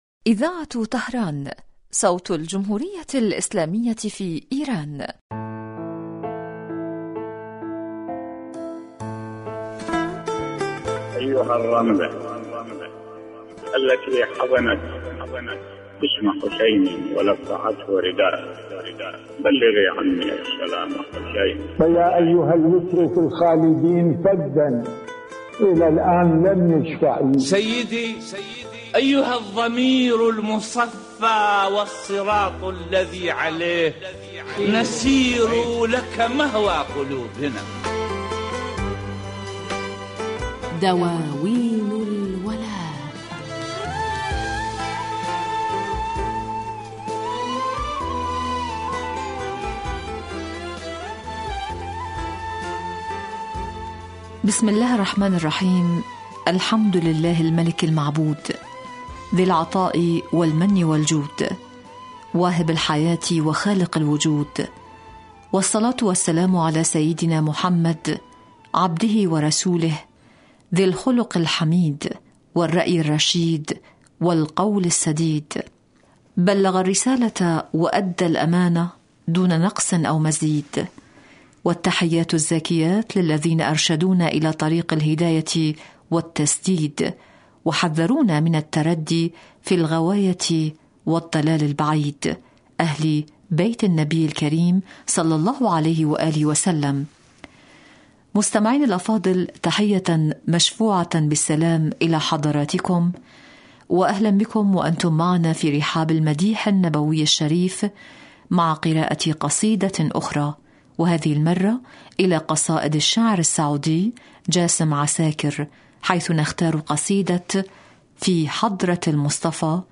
مدائح نبوية